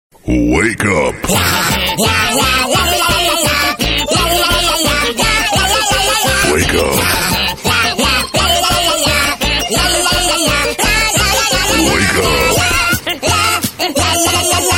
Alarm Tone